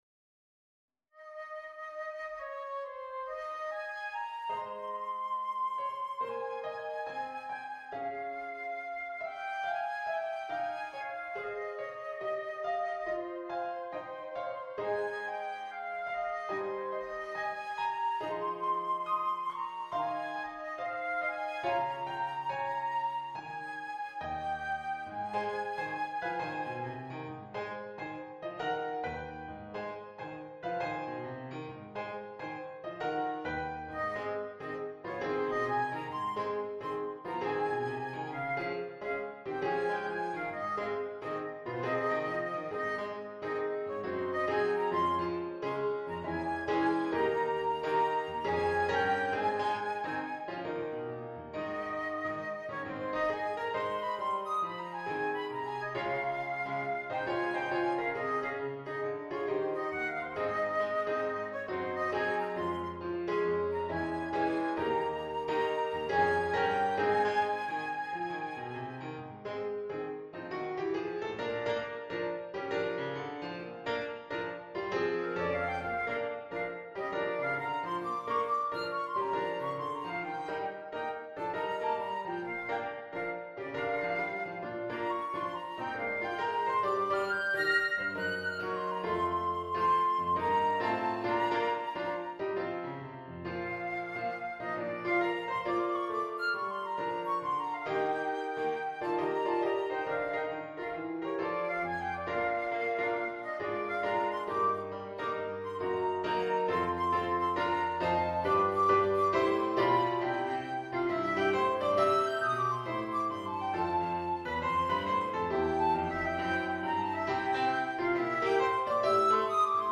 Spirituals